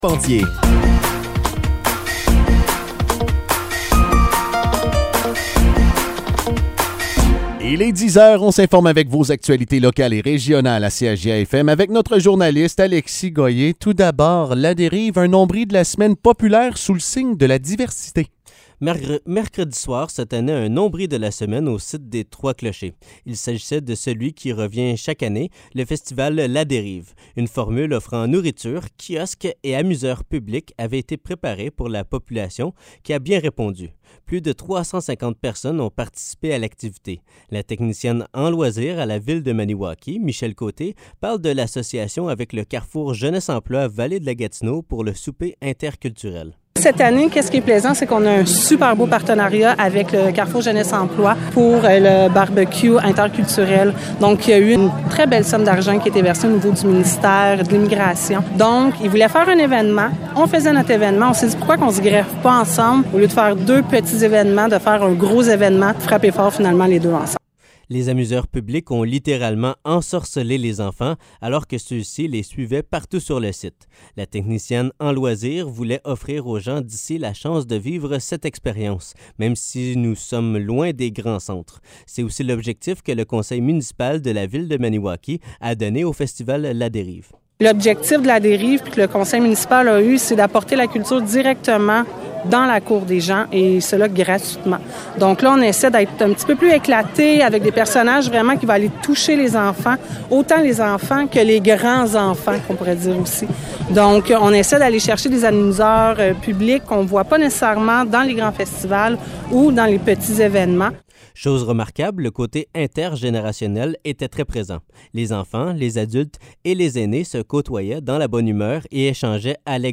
Nouvelles locales - 3 août 2023 - 10 h